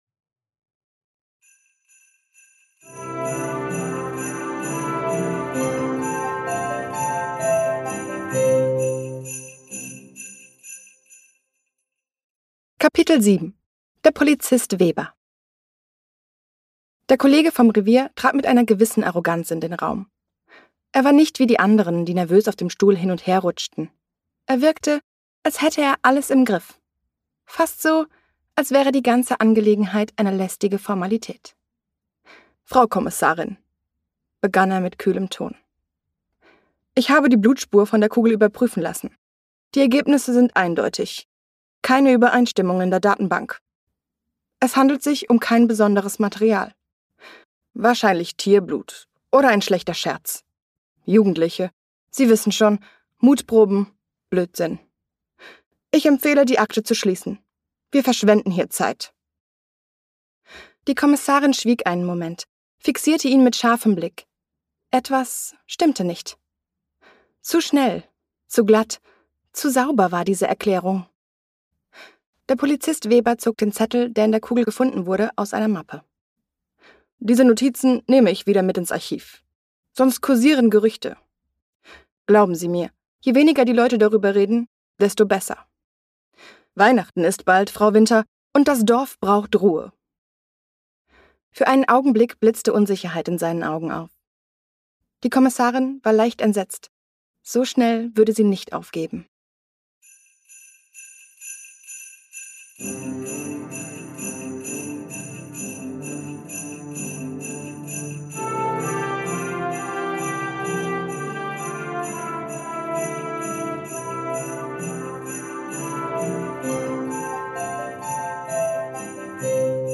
Kriminalgeschichte. Lass dich von acht verzaubernden Stimmen in die